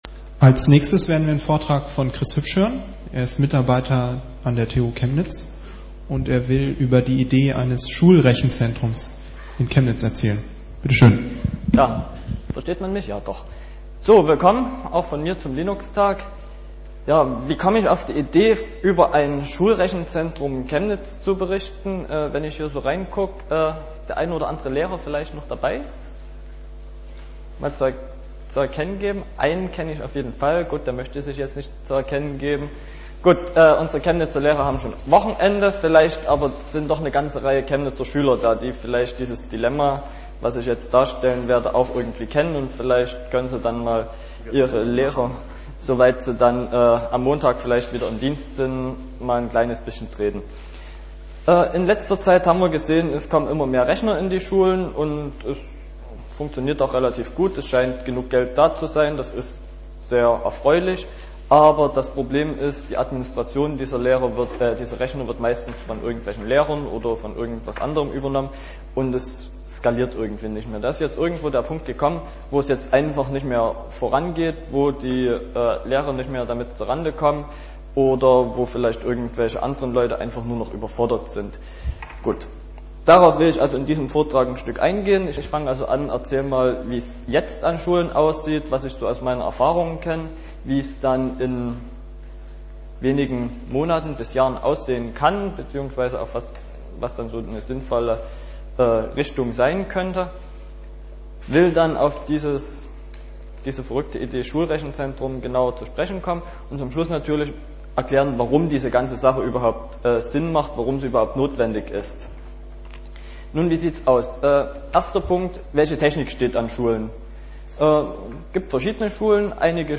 Unterlagen zum Vortrag als pdf (40 kByte) MP3-Mittschnitt vom Vortrag als MP3 (32 kbit/s) (11 MByte) MP3-Mittschnitt vom Vortrag als MP3 (16 kbit/s) (5 MByte)